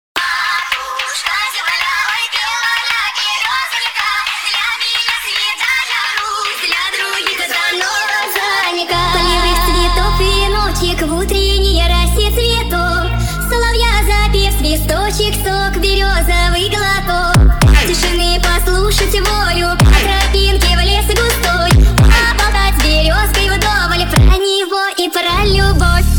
Русские Жанр: Поп Просмотров